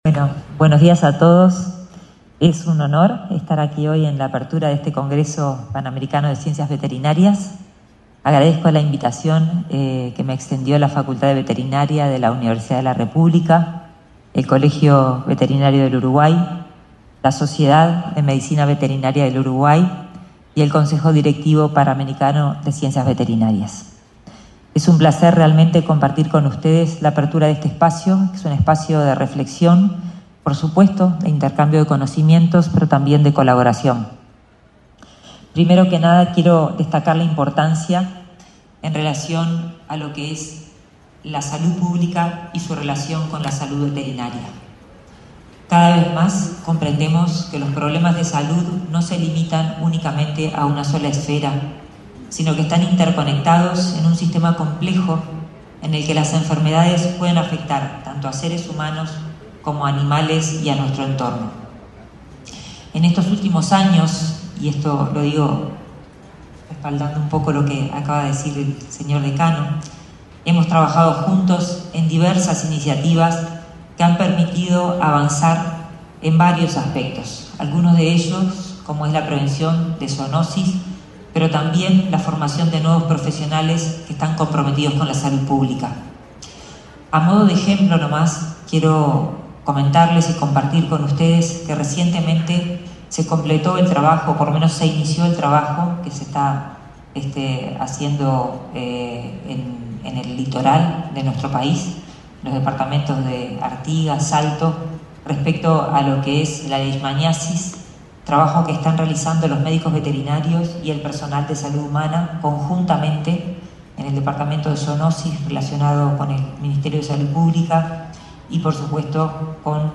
El ministro de Ganadería, Fernando Mattos, dialogó con la prensa, luego de participar, este martes 3 en Montevideo, en el XXVII Congreso Panamericano